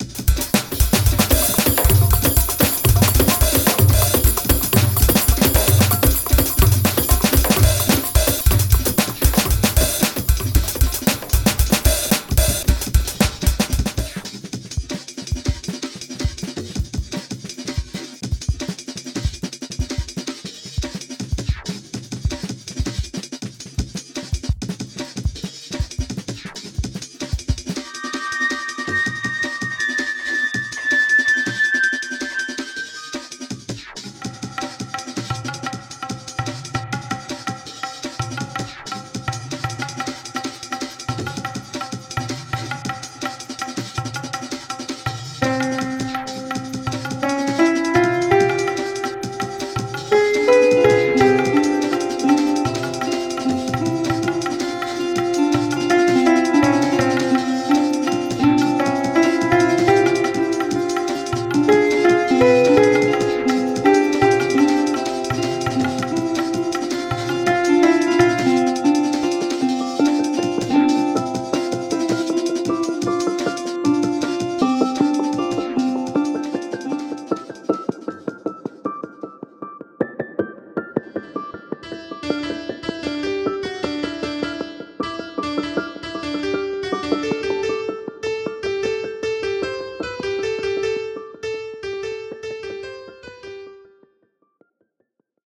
... or this awful background music?!